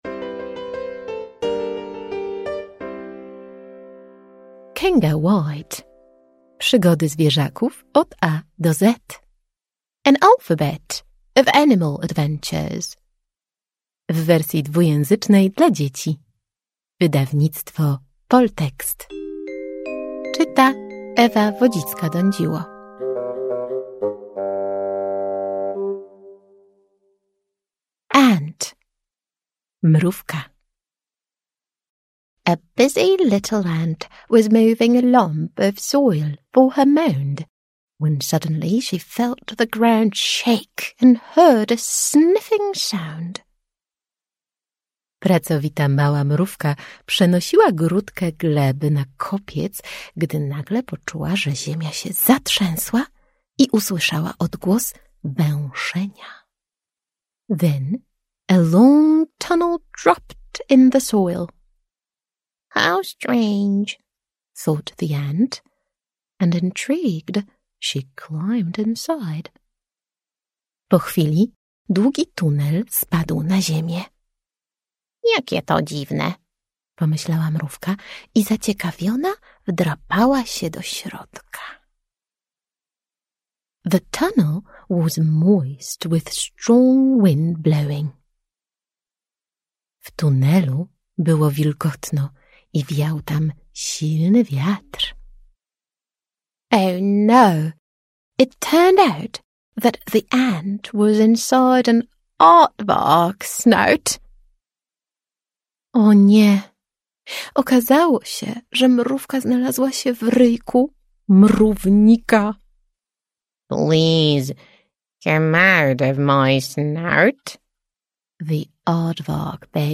AUDIOBOOK (mp3)